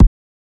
Kick Funk 3.wav